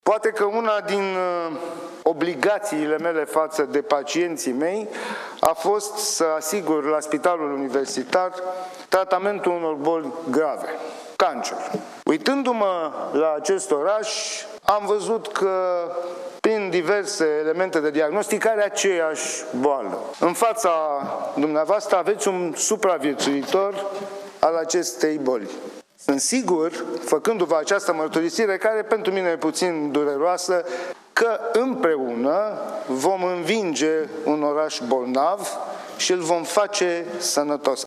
Conferință de presă comună PSDPNL pentru prezentarea candidatului comun la Primăria Capitalei, medicul Cătălin Cîrstoiu.
Medicul Cătălin Cîrstoiu, candidatul la Primăria Capitalei, intervine după 30 de minute: „Împreună vom învinge un oraș bolnav și îl vom face sănătos”